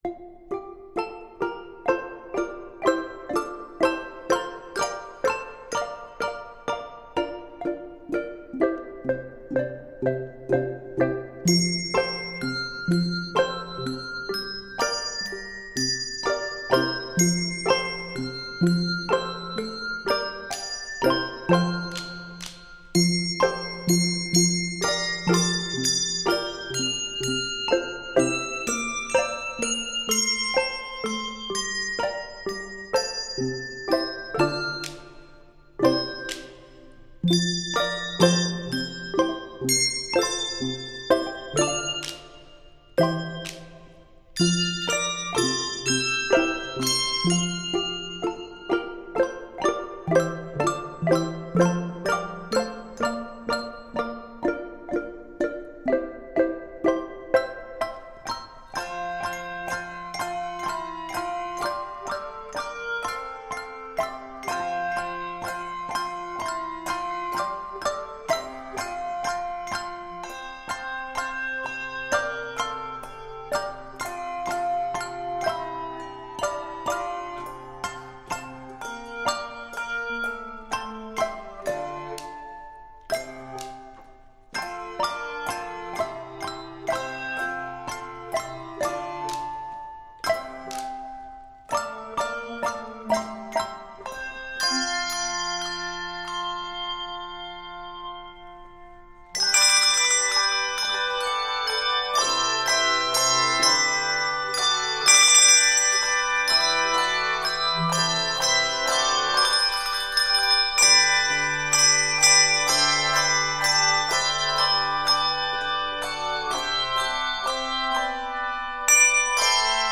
Keys of F Major and G Major.